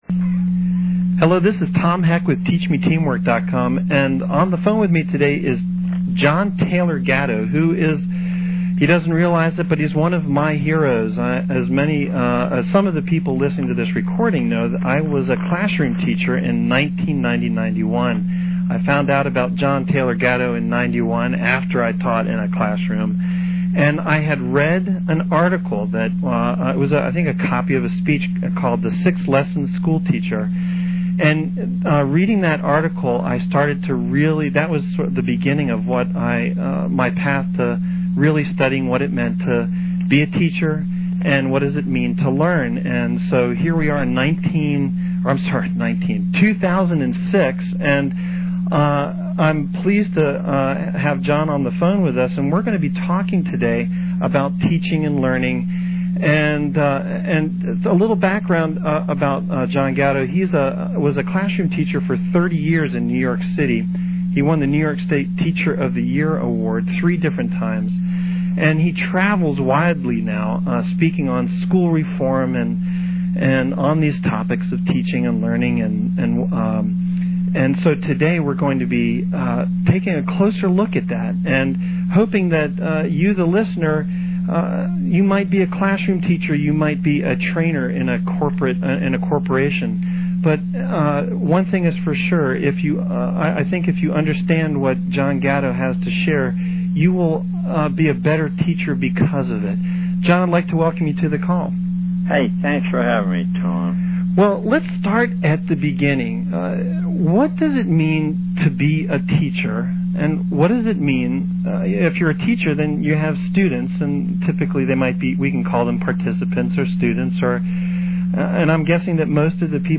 Audio Interview: John Taylor Gatto – brilliant educator, speaker, author – TeachMeTeamwork